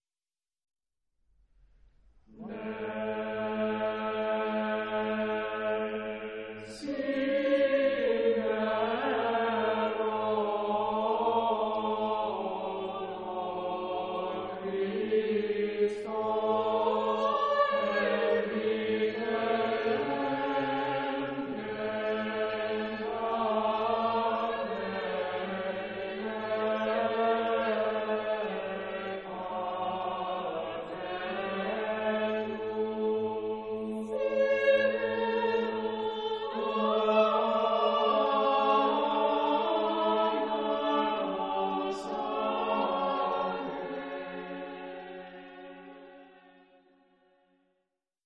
Christmas Music from the Byzantine Tradition